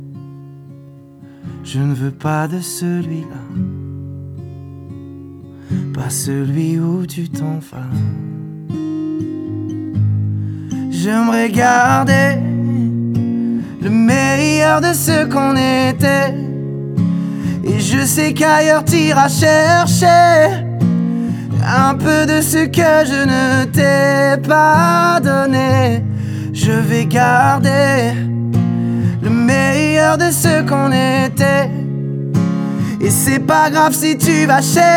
Жанр: Поп
# French Pop